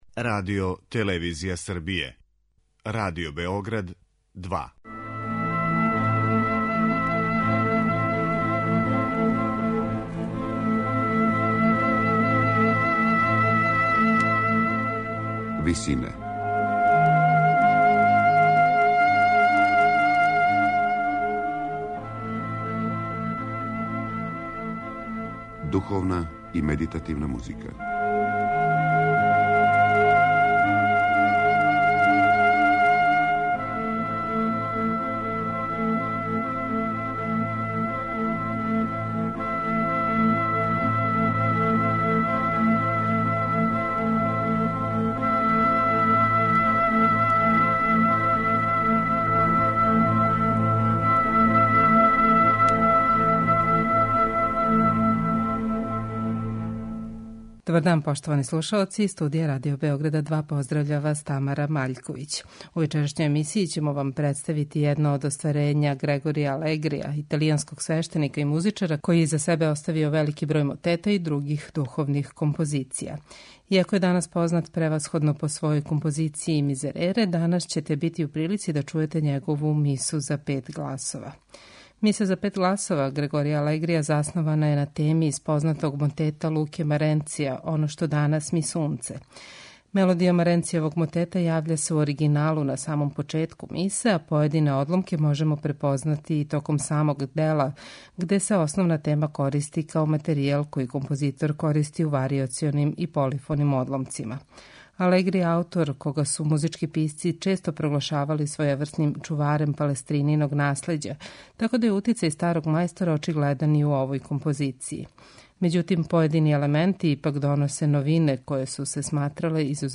бићете у прилици да слушате Мису за пет гласова "Оно што данас ми сунце"
Слушаћете извођење ансамбла Sixteen, под управом Харија Кристоферса, који чини 16 певача специјализвованих за извођење ренесансне музике.